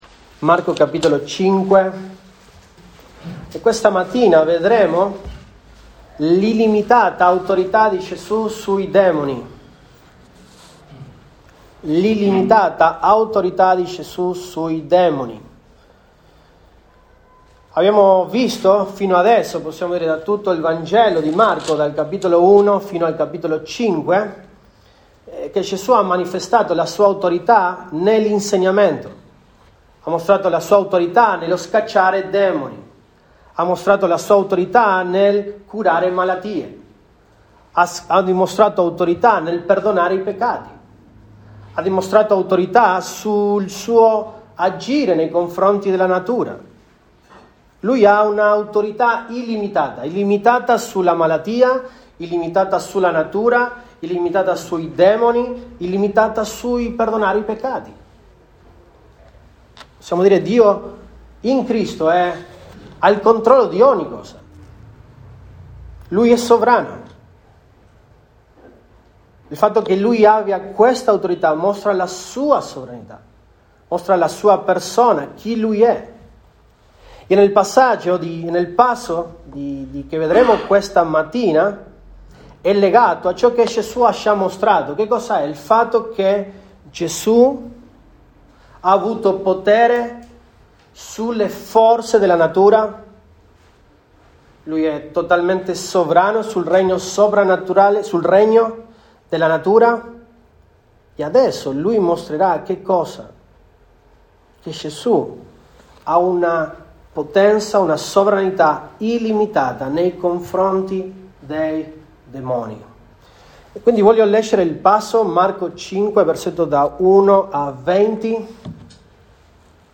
Apr 30, 2023 L’autorità di Gesù Cristo sui demoni MP3 Note Sermoni in questa serie L’autorità di Gesù Cristo sui demoni.